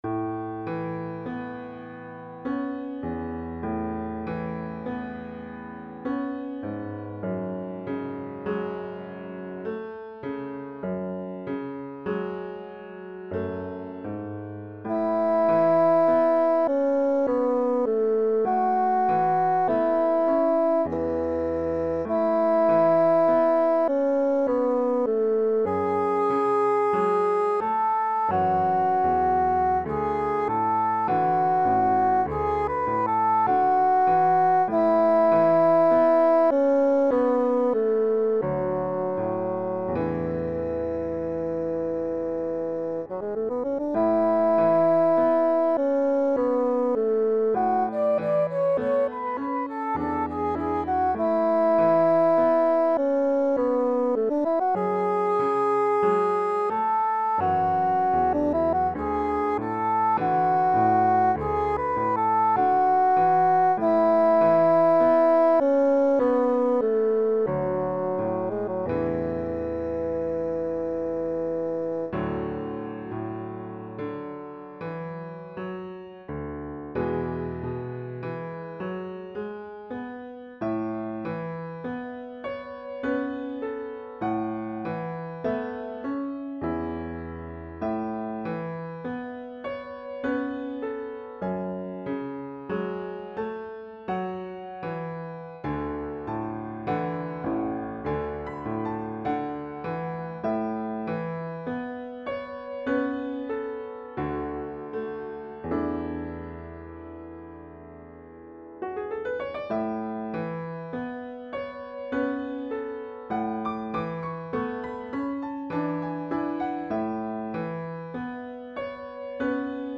Para Fagot y Piano